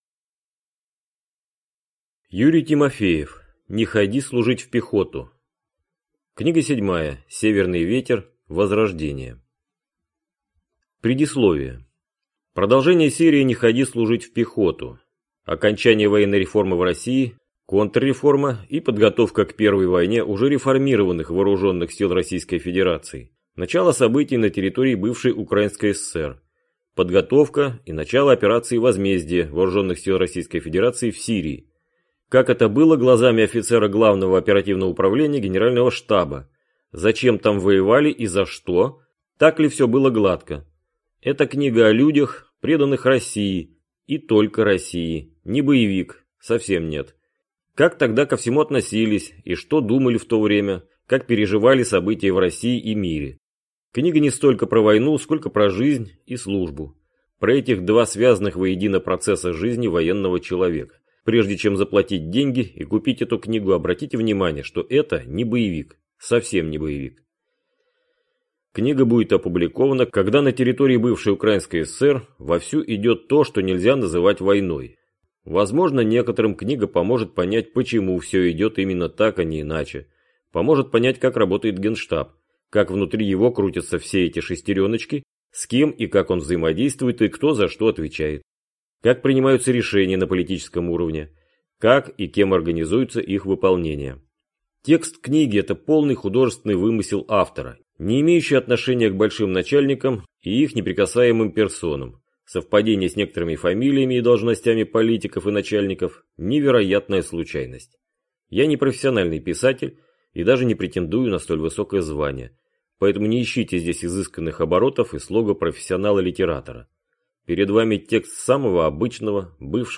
Аудиокнига Не ходи служить в пехоту! Книга 7. Северный ветер. Возрождение | Библиотека аудиокниг